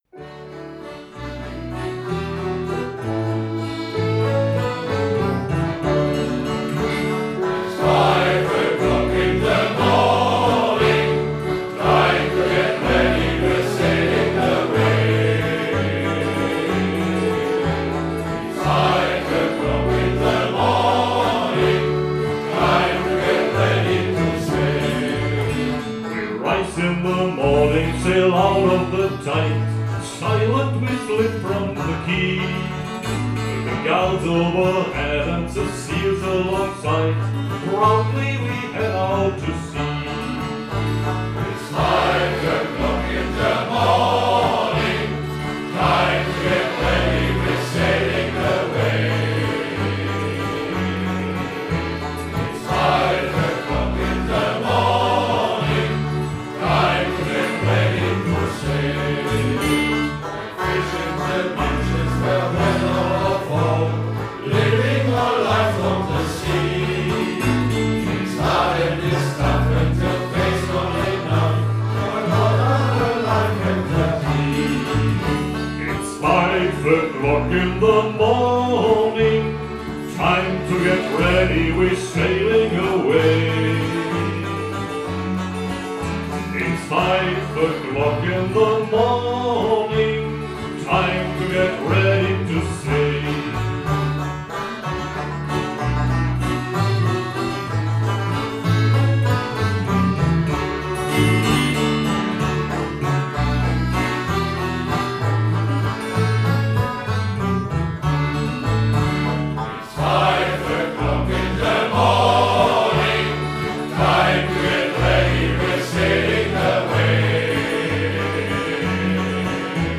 Shanty-Chor der Marinekameradschaft